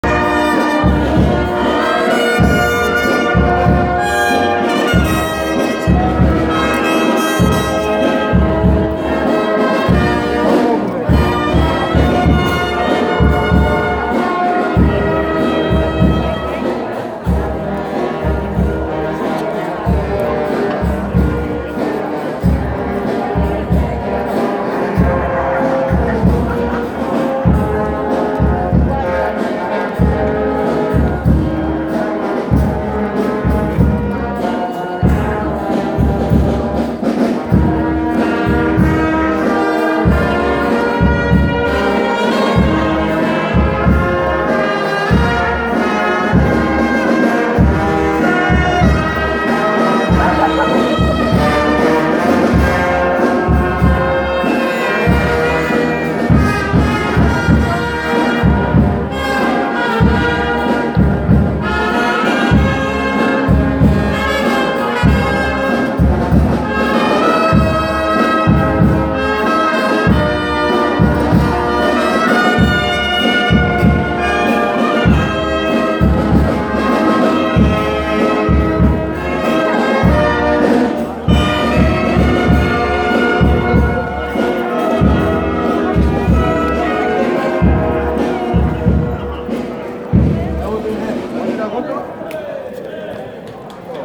Es war Weinfest in Cochem. Überall gab es Musik und Weinstände.
Eine Musikgruppe jagte die nächste.